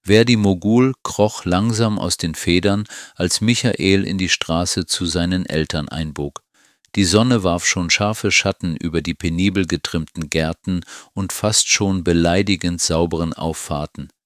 ( Menschliche Stimme) Zum anderen die elektronische.
Sondern eher an eine kostengünsige Brücke zwischen grottenschlechter Vorlesefunktion und einen echten Hörbuch.